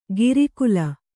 ♪ giri kula